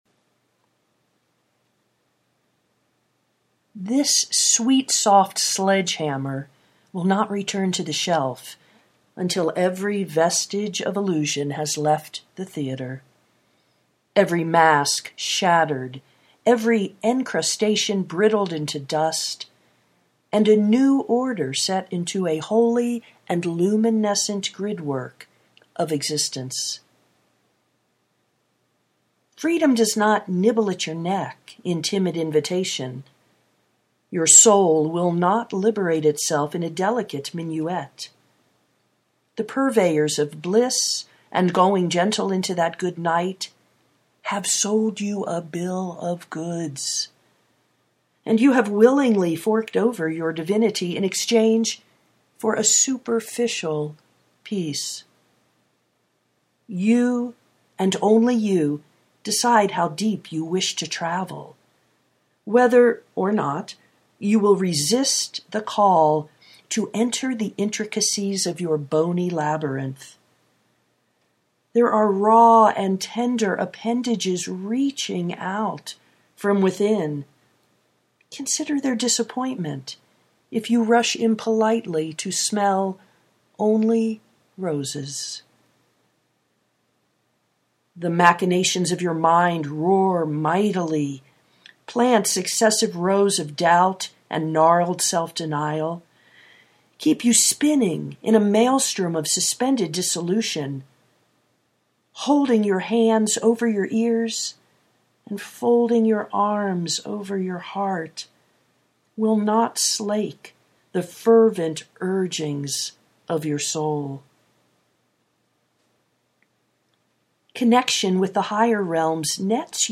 Sweetsoft sledge hammer of the hero’s journey (audio poetry)